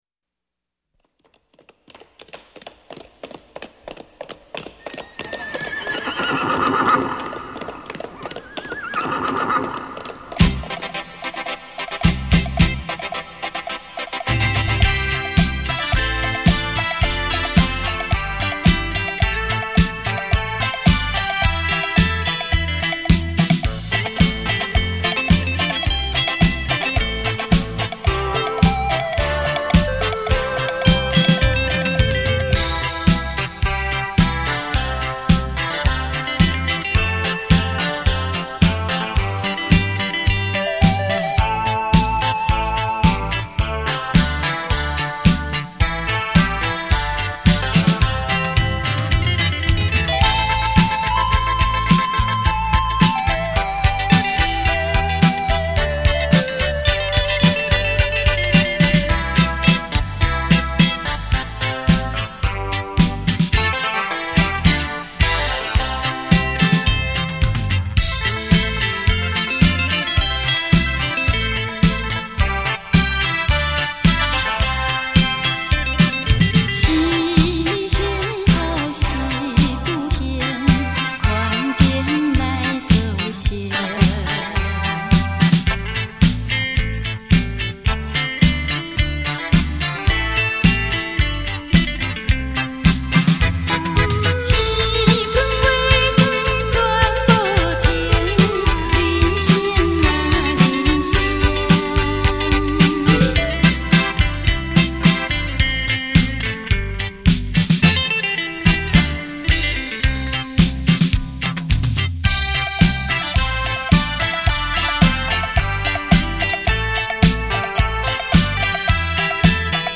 繞場立體音效 發燒音樂重炫
最新編曲快節奏演奏，台語老歌新奏，節奏強勁，
旋律優美，曲曲動聽,電聲演繹發燒珍品·值得您